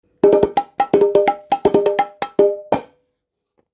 タムタムダブル 素焼き 中
モロッコ、ベルベルの素焼きタイコ、タムタムダブルです。高低2色の音色でボンゴの様な形状です。打面は厚めのやぎ皮が張られ、コシのある音色が特徴。お椀状のボディーで、底がふさがっている為、大きな音量は出ませんが、深みのあるサウンドが楽しめます。セッションや野外フェスにも持っていけるポータブルなサイズです。
素材： 素焼き ヤギ革